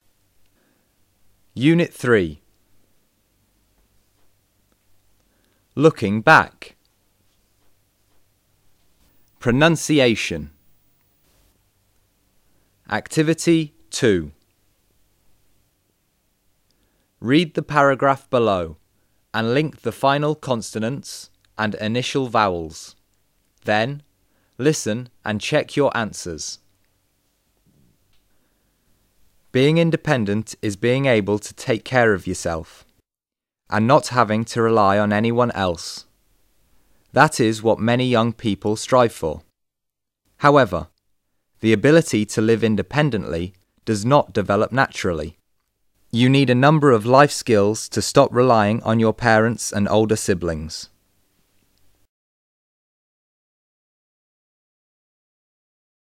2. Read the paraghraph below and link ) the final consonants and initial vowels. Then listen and check your answers.